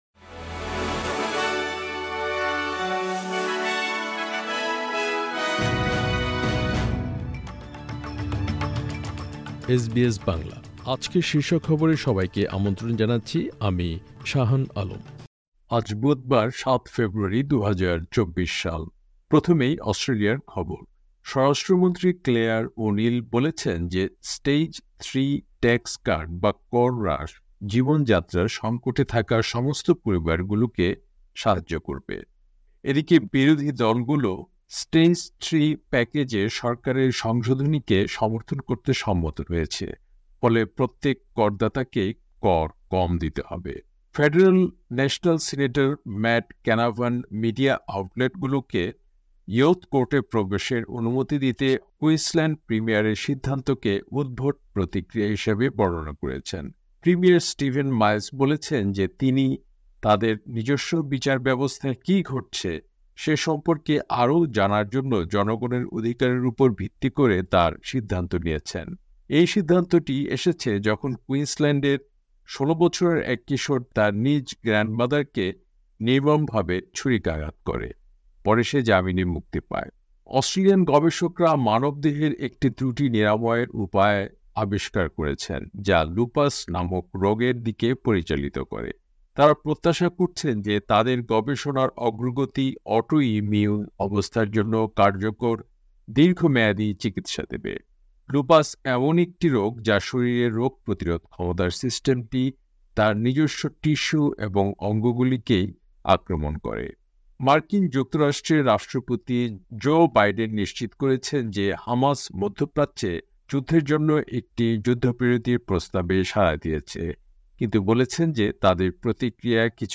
এসবিএস বাংলা শীর্ষ খবর: ৭ ফেব্রুয়ারি , ২০২৪